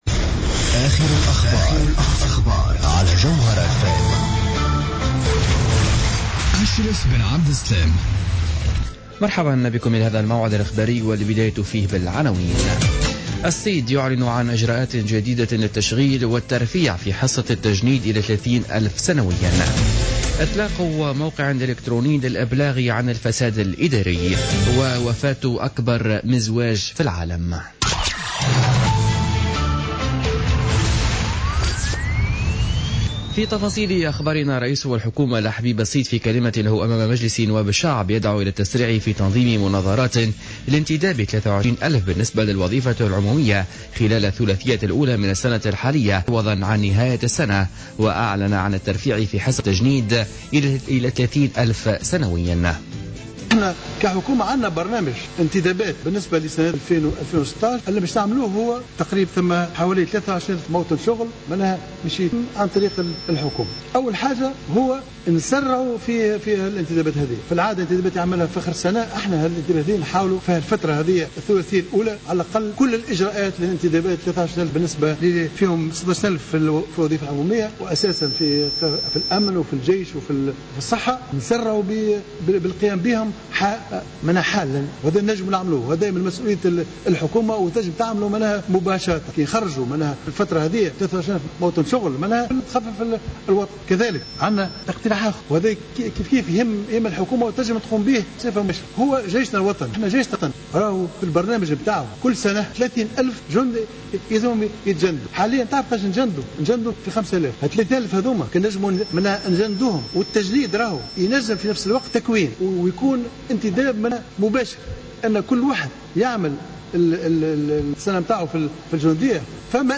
Journal Info 19h00 du jeudi 28 janvier 2016